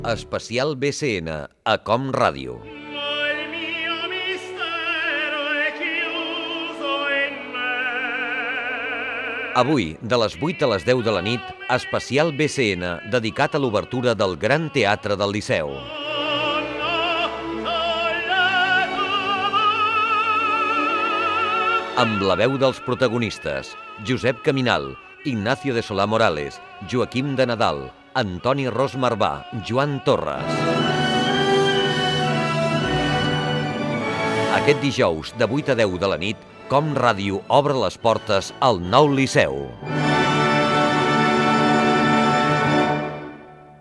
Promoció de l'especial amb motiu de l'obertura del nou Teatre del Liceu de Barcelona.
Informatiu